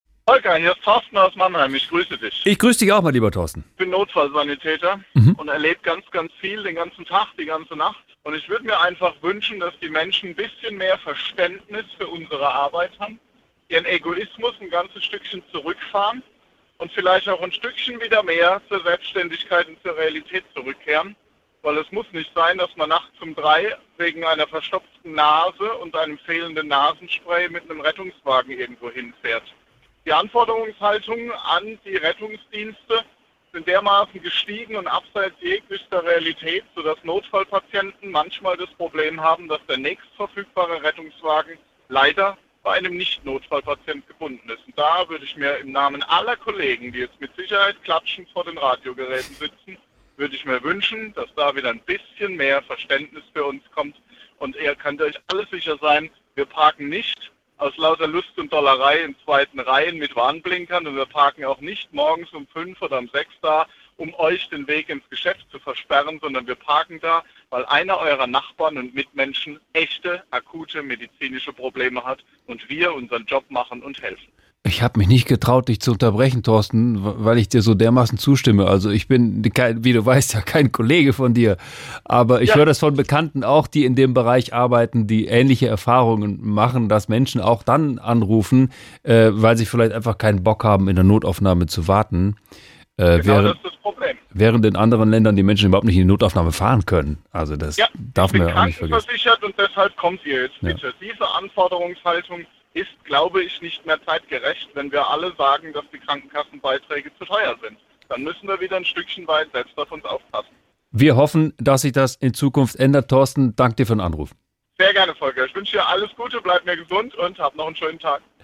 Ähnliches erzählt ein Rettungssanitäter in SWR3: Immer häufiger würden Sanitäter gerufen, obwohl gar kein Notfall vorliegt. Zum Beispiel wenn nachts um drei Uhr die Nase verstopft ist, weil das Nasenspray fehlt.